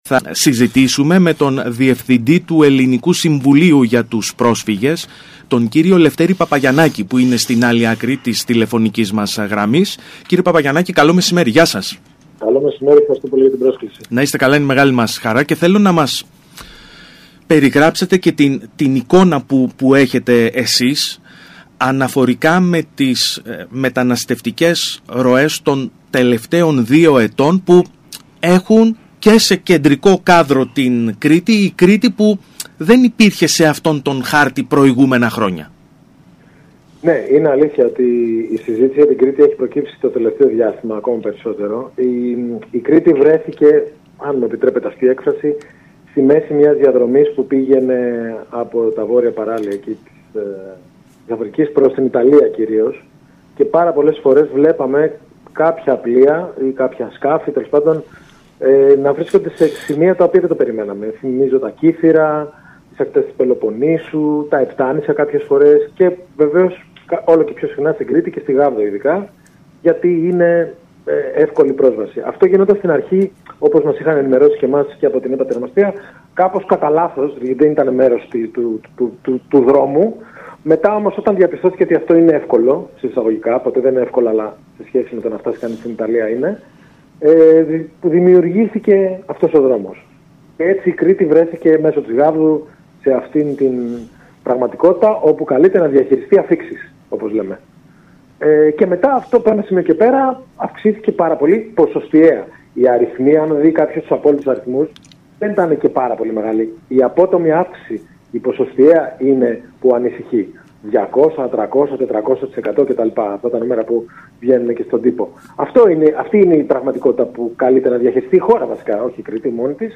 δήλωσε στο ραδιόφωνο του ΣΚΑΪ Κρήτης 92,1